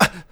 hurt8.wav